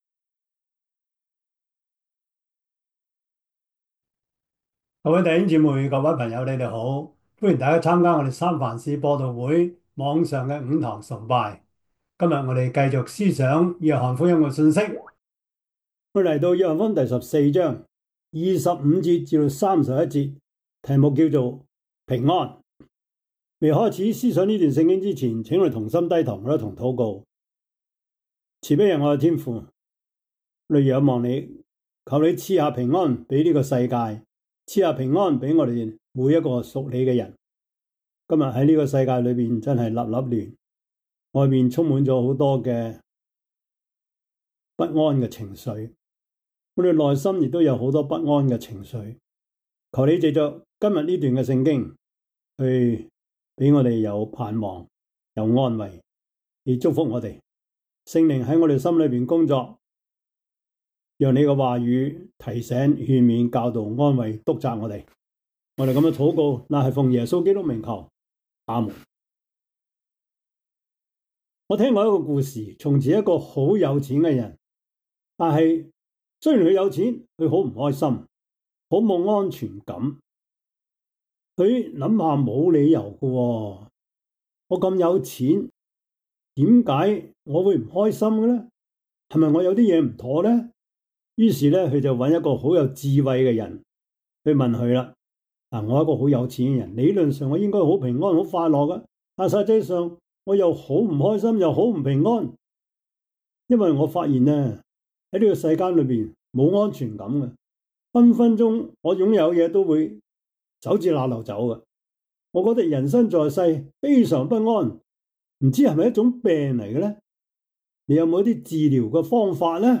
約翰福音 14:25-31 Service Type: 主日崇拜 約翰福音 14:25-31 Chinese Union Version